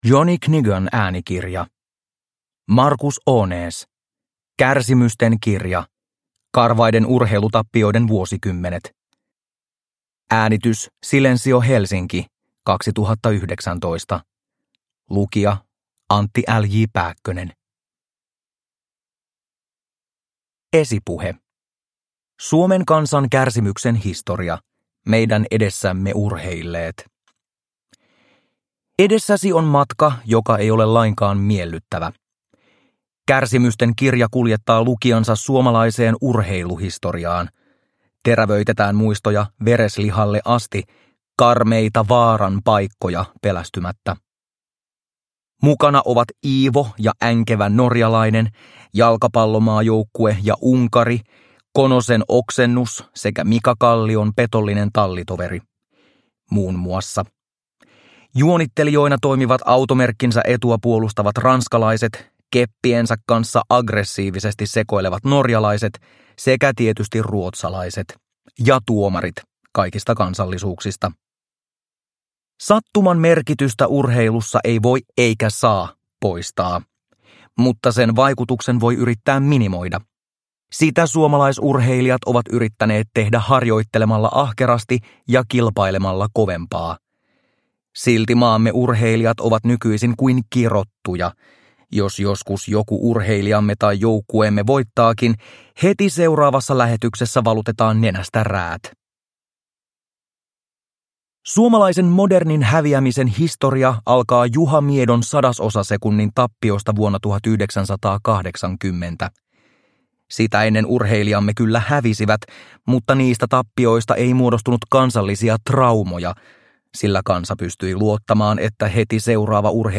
Kärsimysten kirja – Ljudbok – Laddas ner